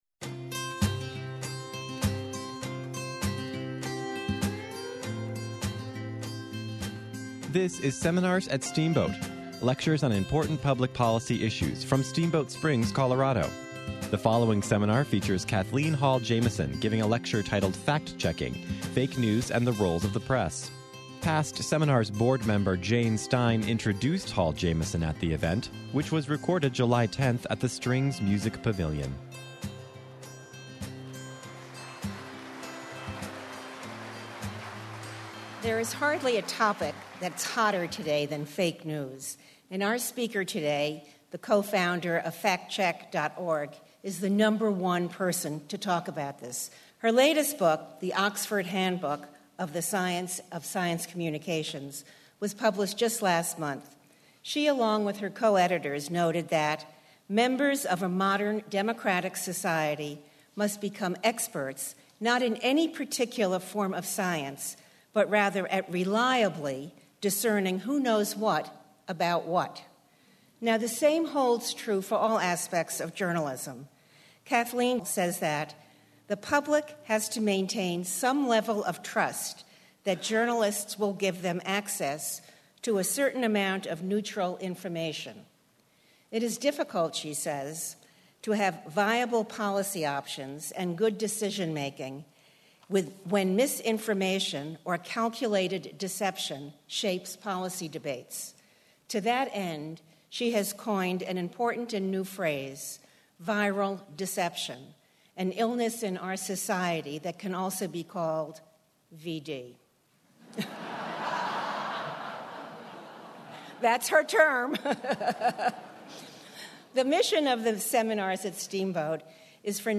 APPC director Kathleen Hall Jamieson moderated panels on civics and fake news at the Ninth Circuit Judicial Conference, and kicked off a seminar series in Steamboat Springs, Colo., with a keynote on fake news.